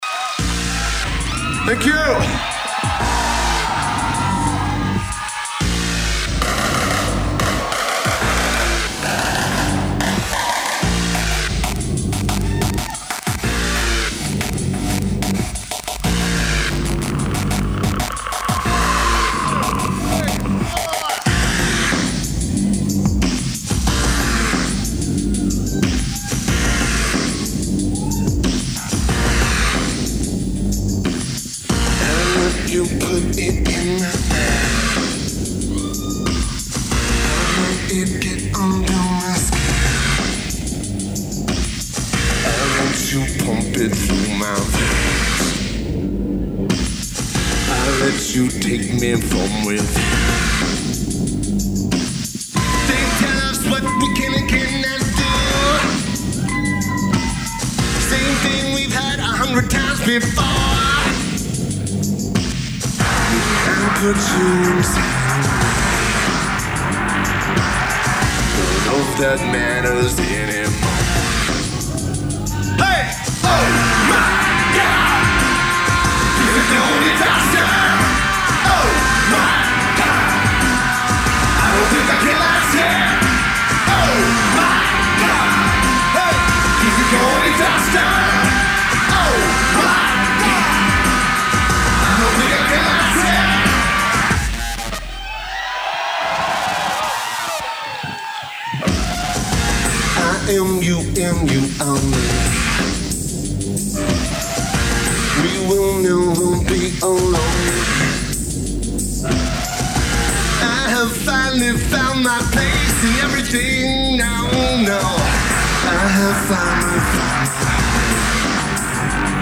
Studio Coast